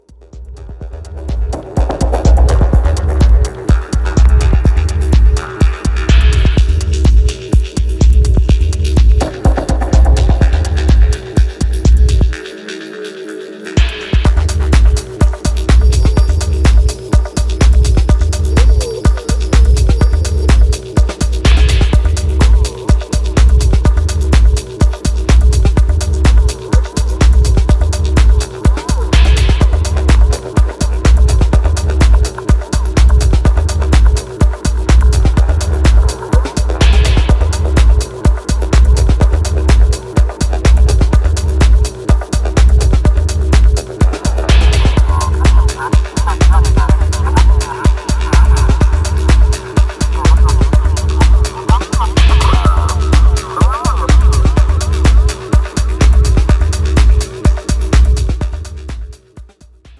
オーガニックな質感と緻密な音響設計が最高なダブ・テクノ作品。
New Release Dub Techno Techno